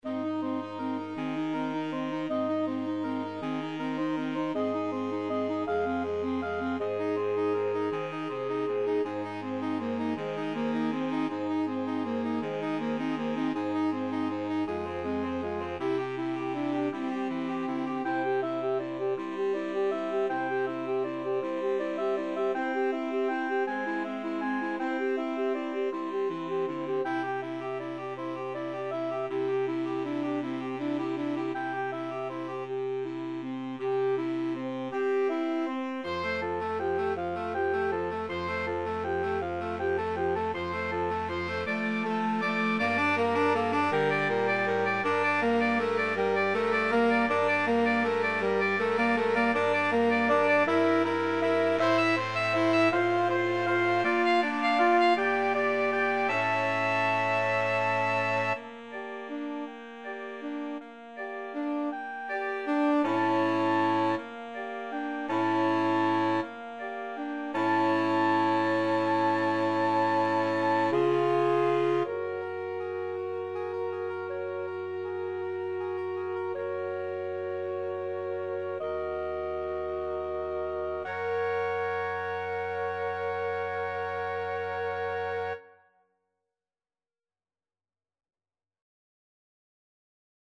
Classical Grieg, Edvard Morning from Peer Gynt (Morning Mood) Saxophone Quartet version
Soprano SaxophoneAlto SaxophoneTenor SaxophoneBaritone Saxophone
Ab major (Sounding Pitch) (View more Ab major Music for Saxophone Quartet )
6/8 (View more 6/8 Music)
Andante
Classical (View more Classical Saxophone Quartet Music)